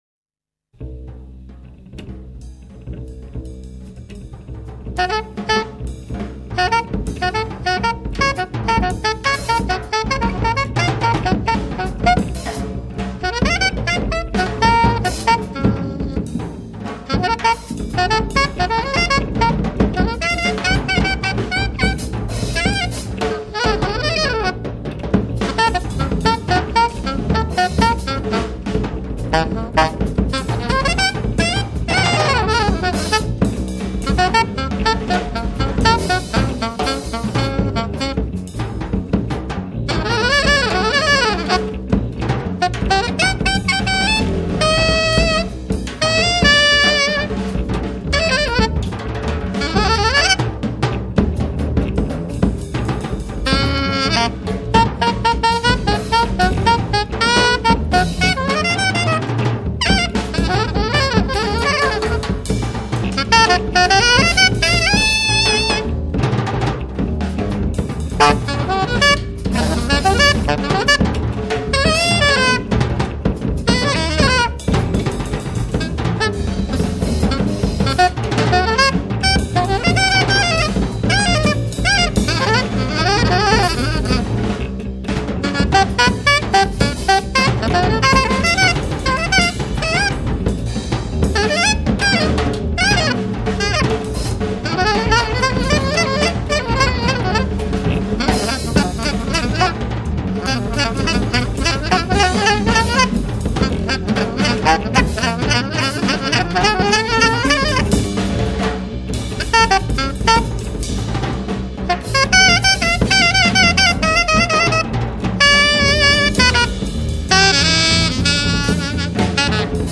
037: Trio, Studio Rivbea, NYC, June 30, 1975